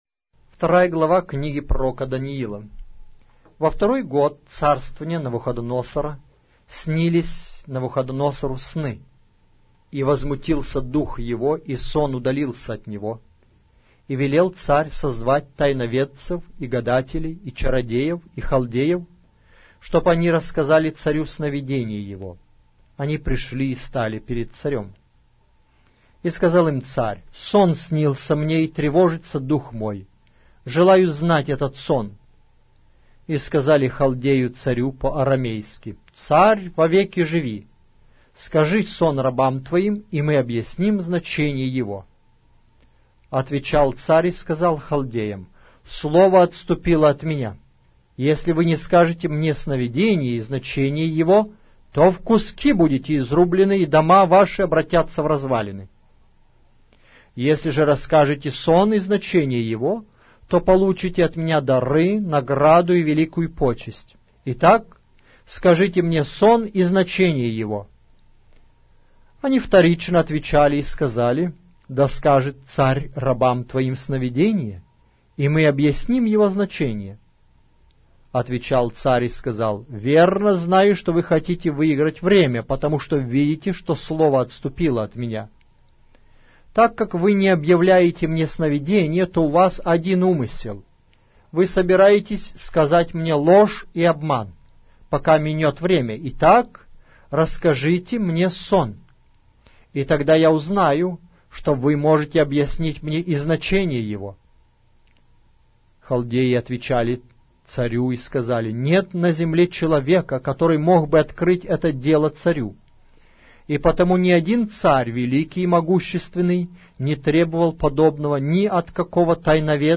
Аудиокнига: Пророк Даниил